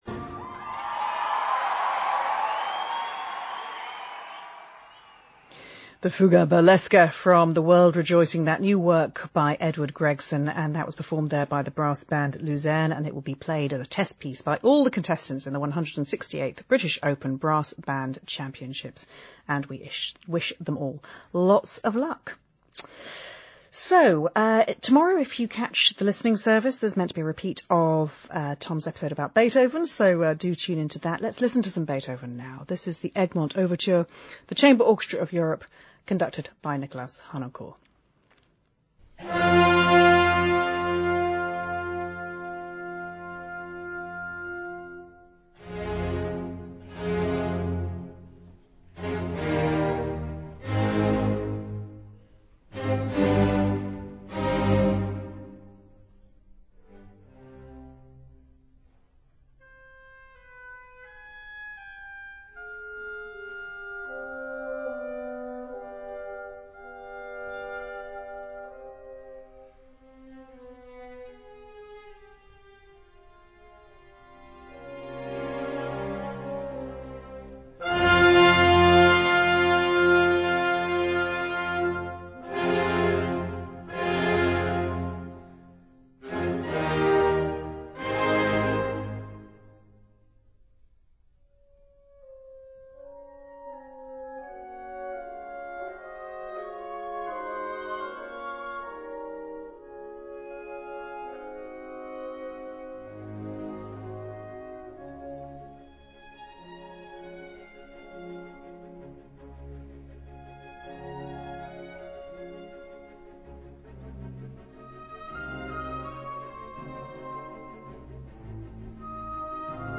BBC Radio 3 enters OBIT for The Queen